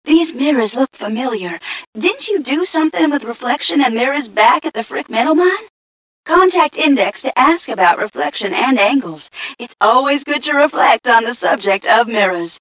1 channel
mission_voice_m4ca003.mp3